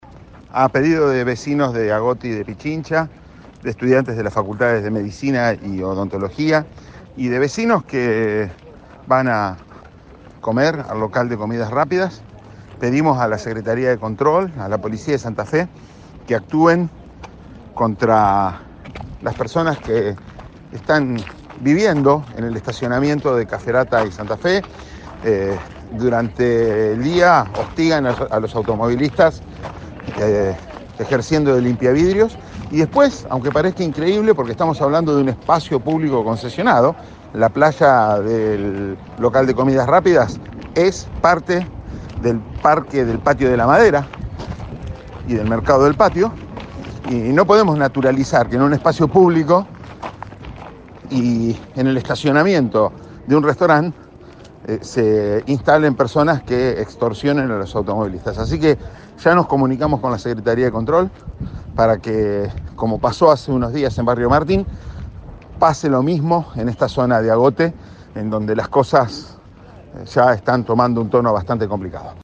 “Pedimos a la Secretaría de Control de la Municipalidad de Rosario y a la policía de Santa Fe que actúen contra las personas que están viviendo en el estacionamiento de Caferatta y Santa Fe que hostigan y extorsionan a las personas que pasan por ese lugar”, le dijo el concejal a Primera Plana en Cadena 3 Rosario.